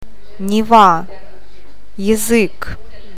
O ääntyy painottomana a :n kaltaisena, e ja я i :n tai ji :n tapaisena äänteenä.